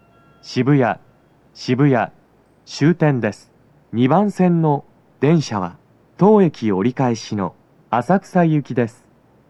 スピーカー種類 TOA弦型、TOA天井型() ※収録音声は全てTOA弦型での収録。
駅員放送が被りやすく、多少の粘りが必要です。
男声
到着放送1